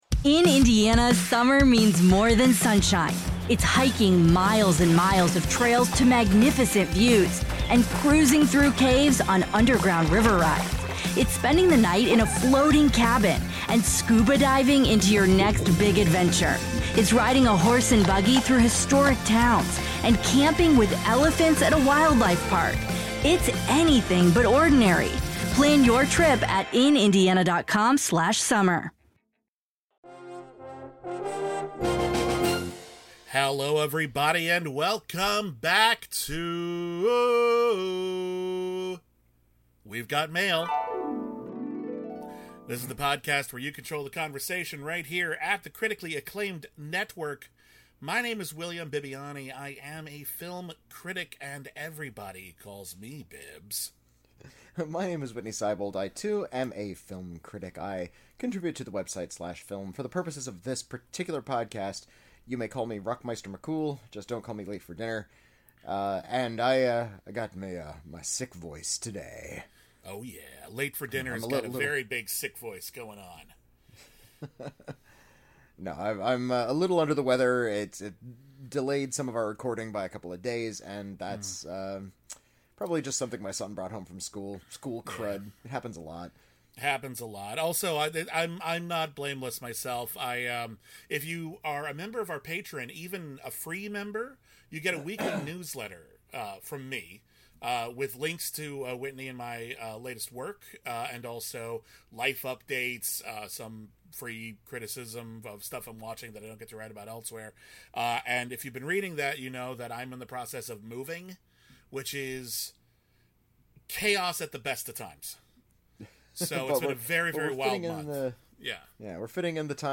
Doomsday Mixtape is a short actual play mini-series from Crit or Miss!